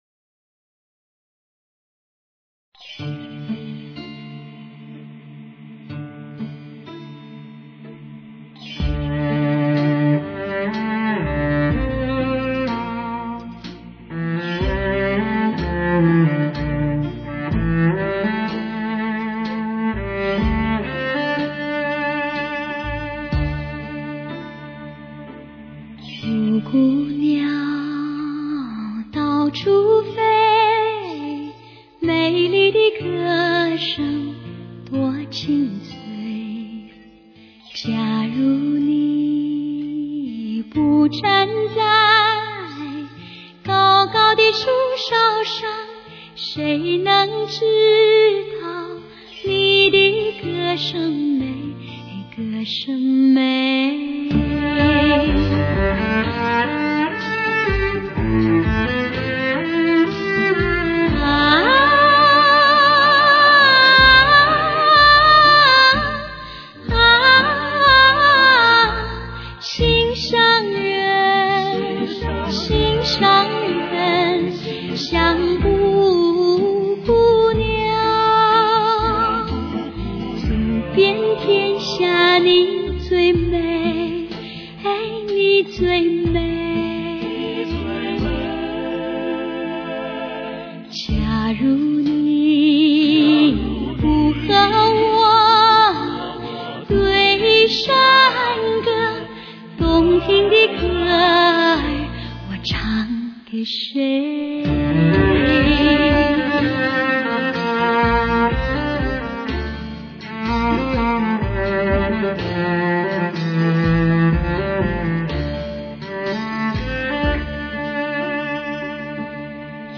至真至靓至美人声
顶级发烧录音制作
它的音效动态更大更明显，音场更宽广、透明。
飘渺的歌声，丝绸般的爽滑质感，对传统作品既尊重又有所超越的演唱技巧，